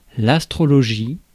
Ääntäminen
Synonyymit astromancie Ääntäminen France: IPA: /as.tʁɔ.lɔ.ʒi/ Haettu sana löytyi näillä lähdekielillä: ranska Käännös Substantiivit 1. astrology Suku: f .